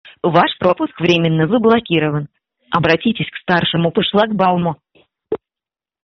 Те жители дома, кто будет блокирован, при наборе номера шлагбаума на въезд или на выезд: услышат такую фразу.